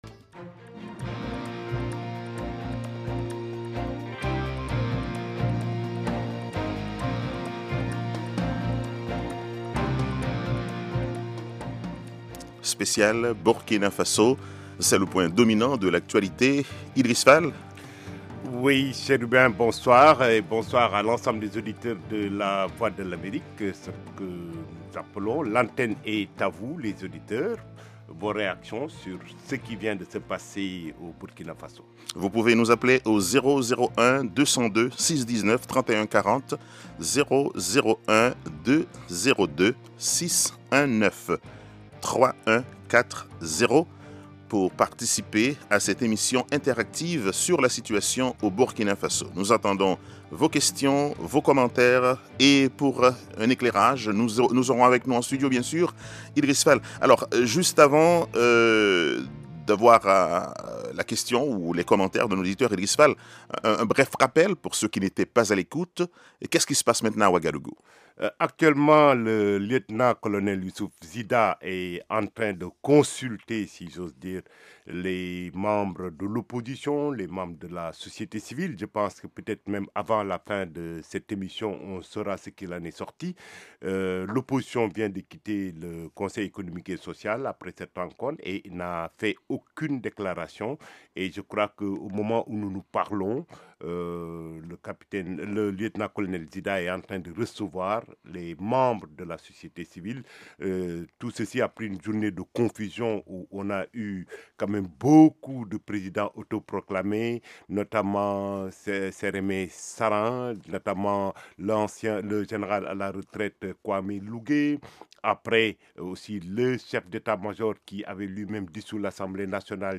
Blues and Jazz Program